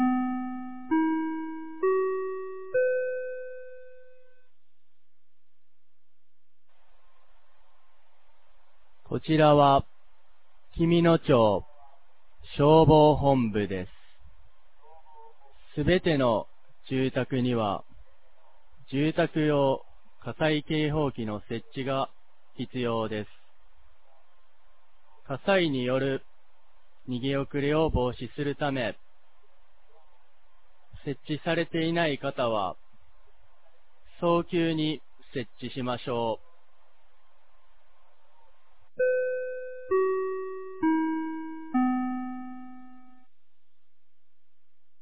2023年12月09日 16時00分に、紀美野町より全地区へ放送がありました。